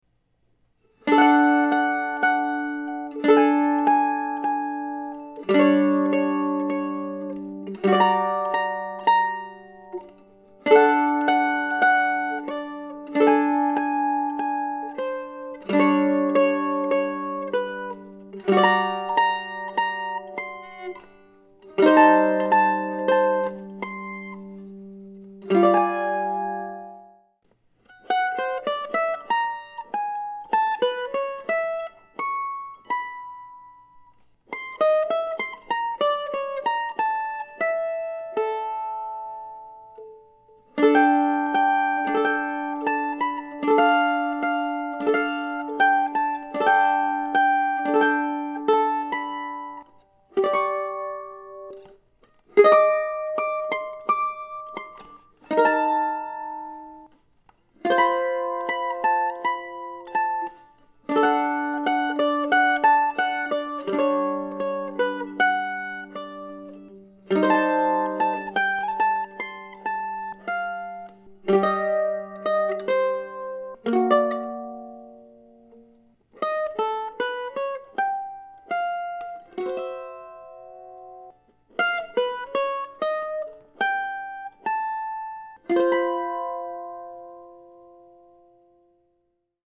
In April of 2018 I wandered onto another Deer Track and I have played this one numerous times at my semi-regular solo mandolin gigs in Decorah since then.